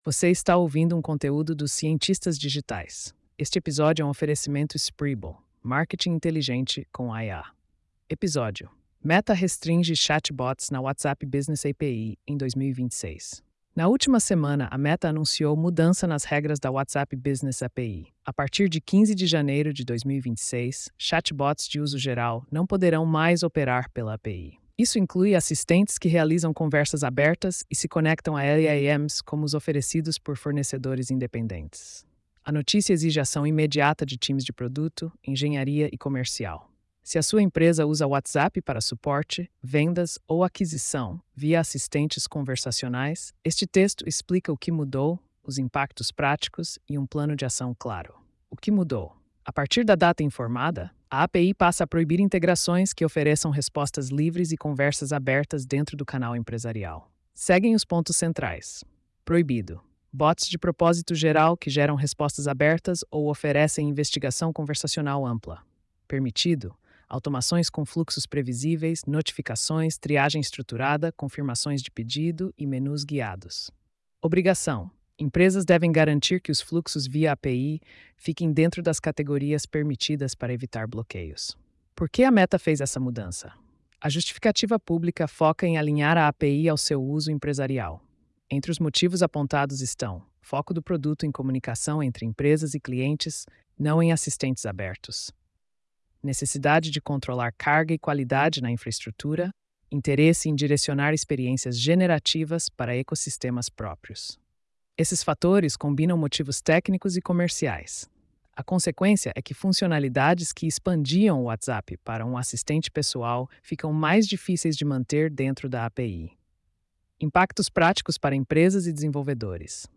post-4504-tts.mp3